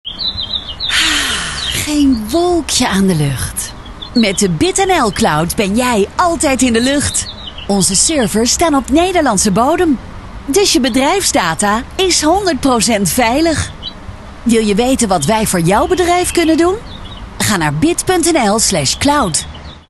BIT-NL-CLOUD-COMMERCIAL.mp3